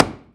WoodFall1.wav